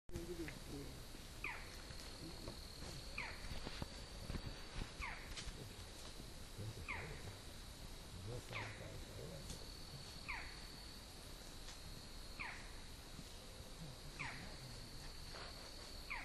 Falcão-caburé (Micrastur ruficollis)
Nome em Inglês: Barred Forest Falcon
Fase da vida: Adulto
Localidade ou área protegida: Reserva Privada y Ecolodge Surucuá
Condição: Selvagem
Certeza: Gravado Vocal
halcon-montes-chico.mp3